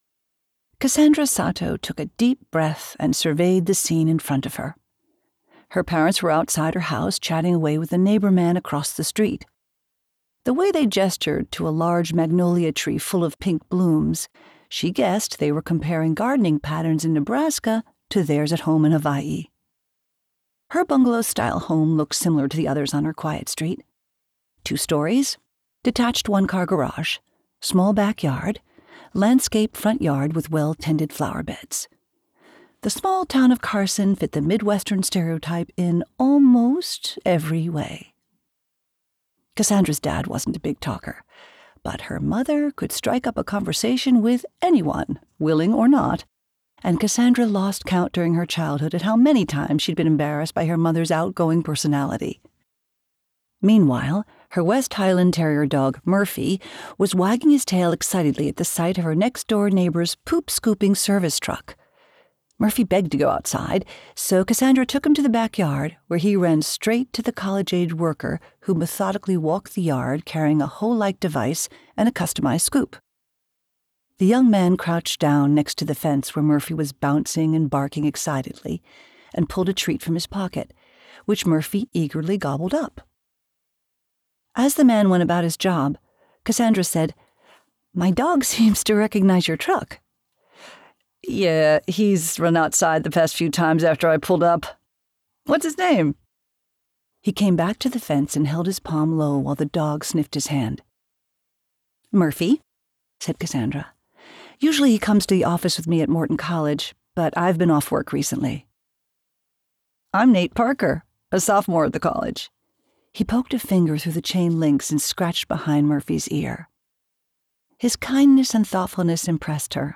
death 101: extra credit audiobook cover